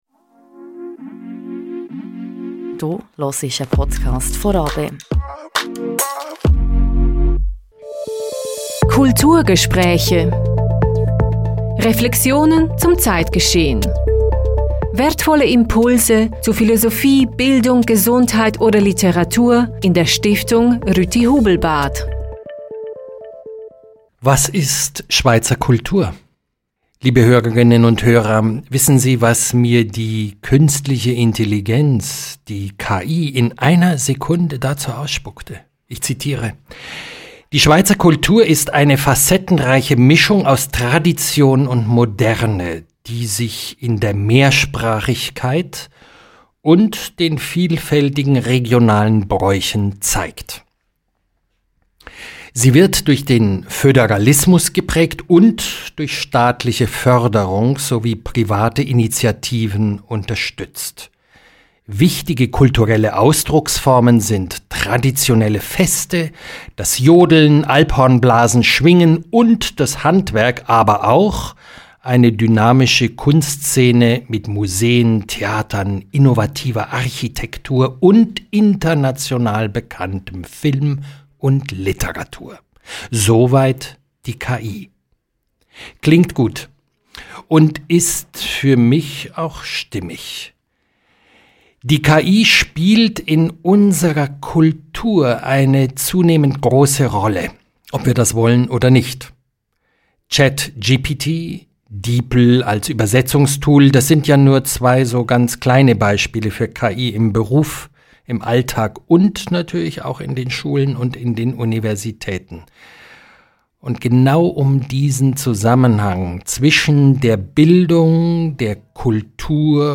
Ein aufrüttelndes Gespräch